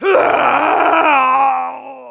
Voice file from Team Fortress 2 German version.
Demoman_paincrticialdeath02_de.wav